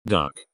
Tags: voice control robot